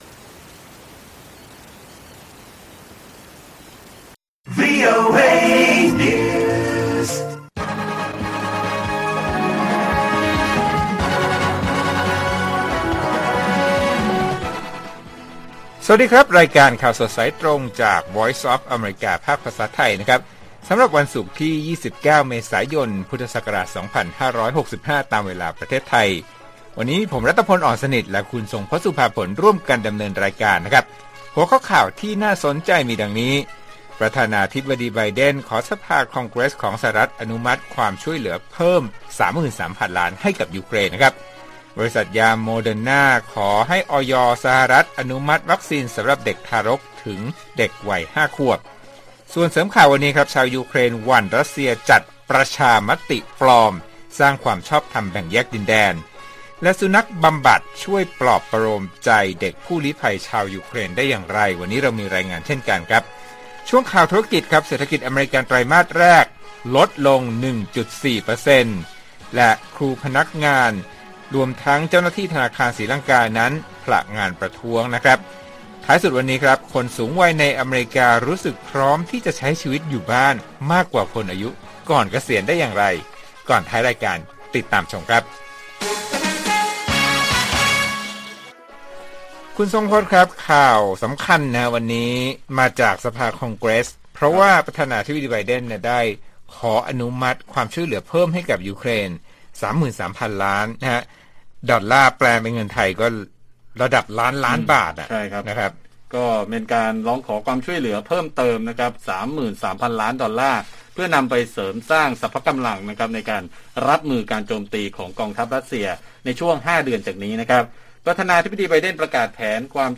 ข่าวสดสายตรงจากวีโอเอ ภาคภาษาไทย 8:30–9:00 น. วันที่ 29 เม.ย. 65